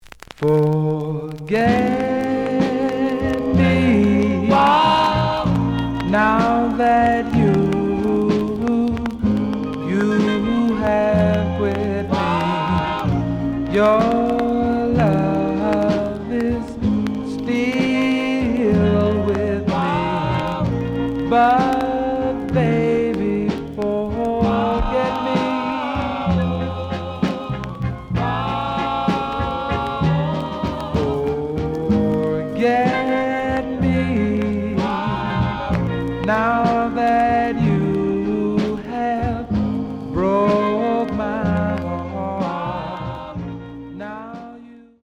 The audio sample is recorded from the actual item.
●Genre: Soul, 60's Soul
Some click noise on B side due to scratches.)